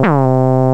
LO ARP.wav